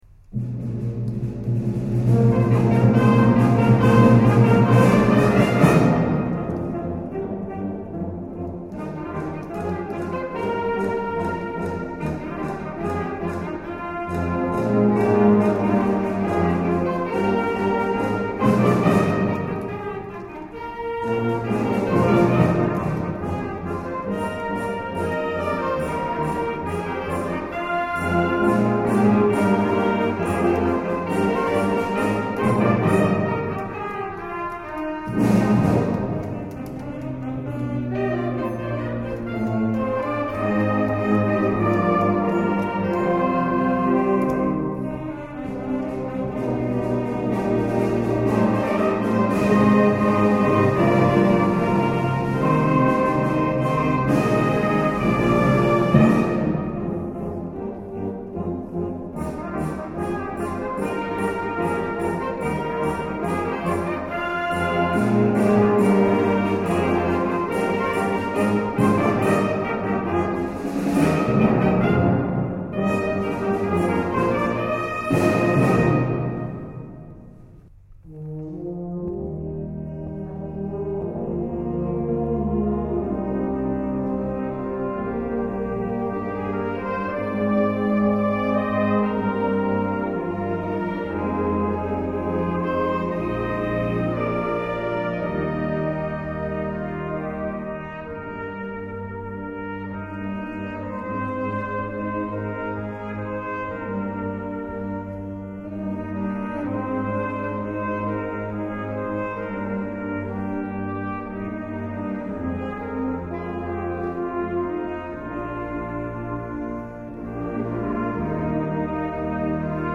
BANDA MUSICALE
Concerto di Natale 2010